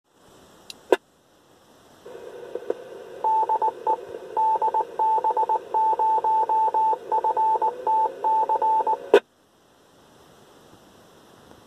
[IV]: Die Relaisfunkstellen Kennung:
Kennung.mp3